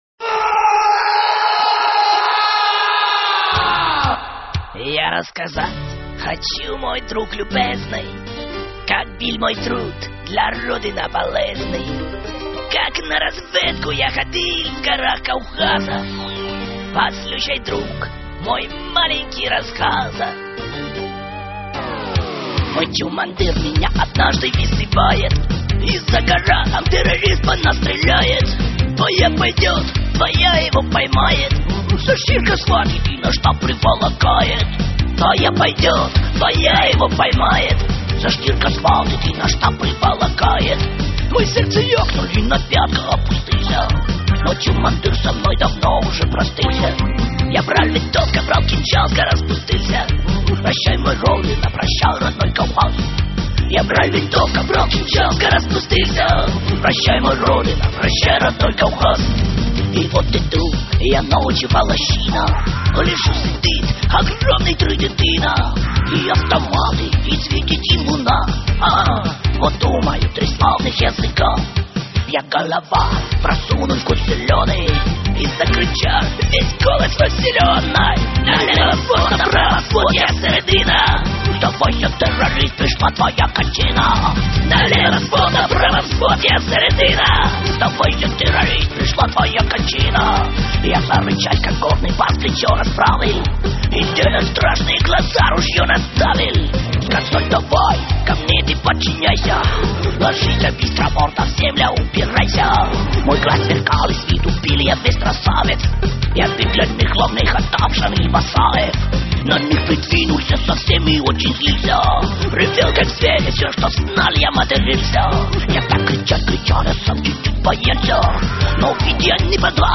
Просто грустная песня